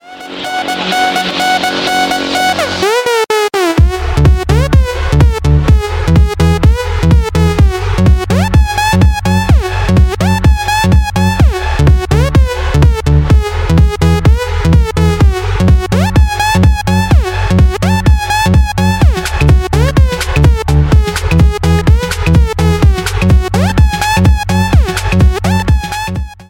Электроника # без слов
клубные